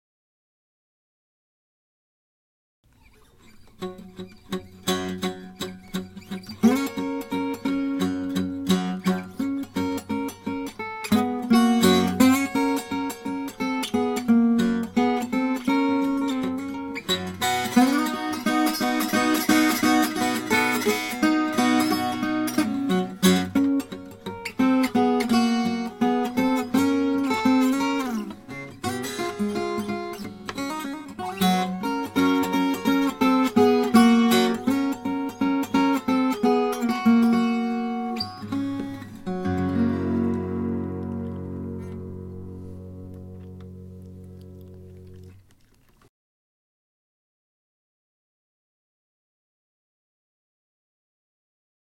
Мне нравится мой салон из белого дуба.